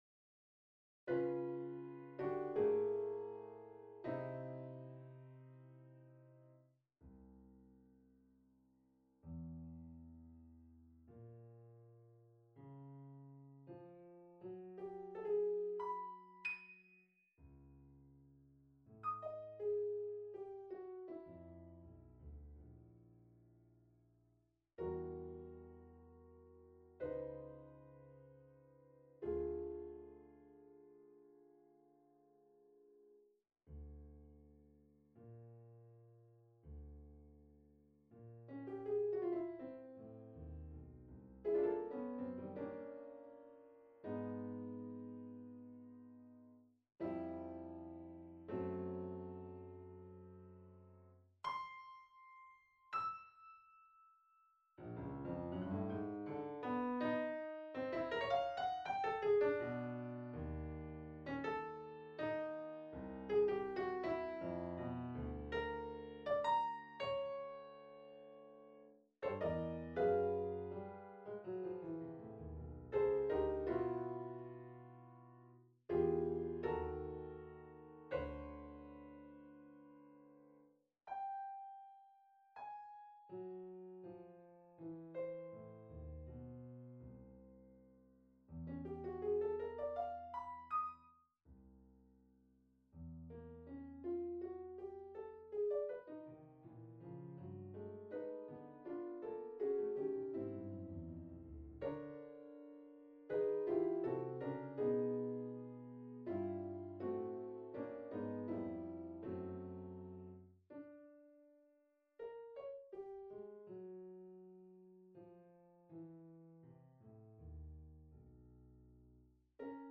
Sonata for Piano No.2, Op.35
Sonata for Piano No.2 on a purpose-selected tone row Op.35 1. Andante espansivo 2.
Presto con serenit� Date Duration Download 14 December 2012 22'14" Realization (.MP3) Score (.PDF) 30.5 MB 284 KB